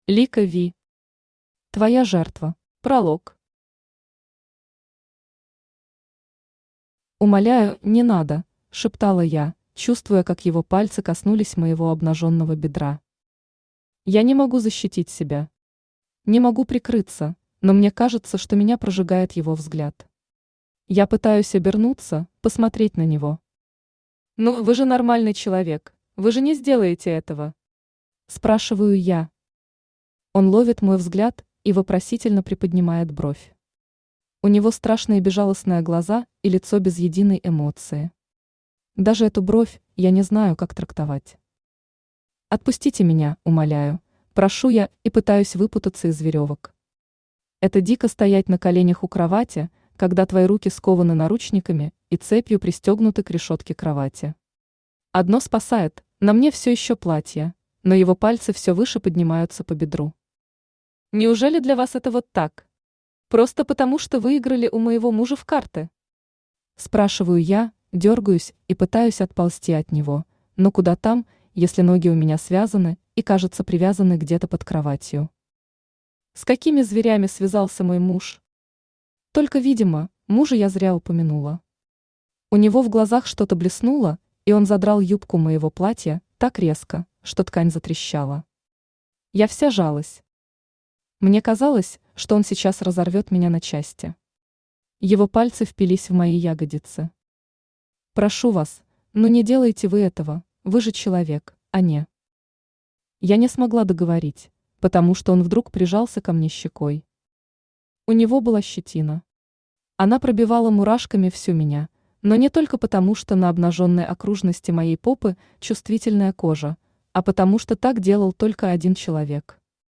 Аудиокнига Твоя жертва | Библиотека аудиокниг
Aудиокнига Твоя жертва Автор Лика Ви Читает аудиокнигу Авточтец ЛитРес.